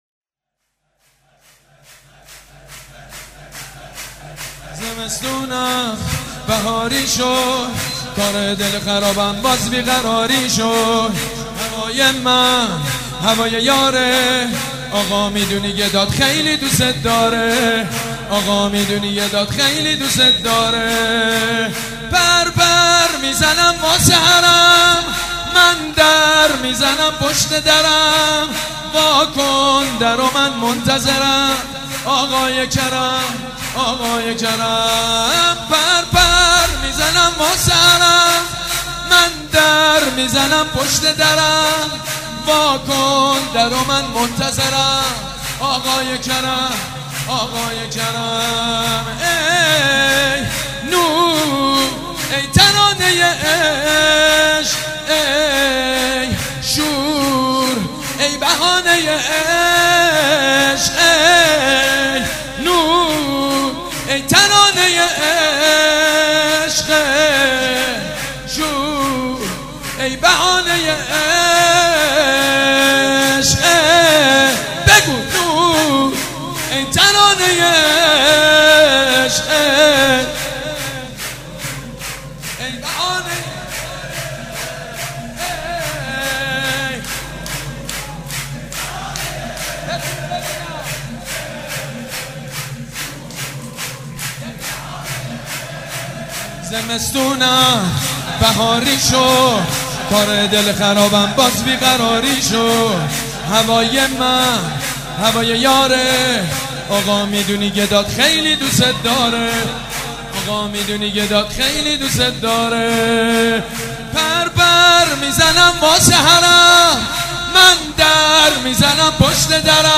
شور: زمستونم بهاری شد